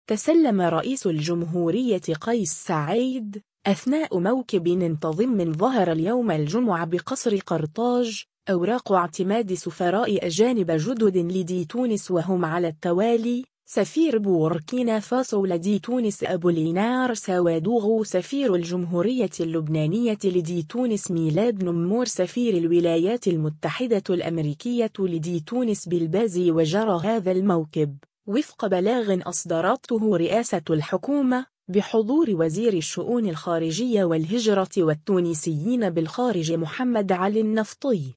تسلّم رئيس الجمهورية قيس سعيّد، أثناء موكب انتظم ظهر اليوم الجمعة بقصر قرطاج، أوراق اعتماد سفراء أجانب جدد لدى تونس و هم على التوالي :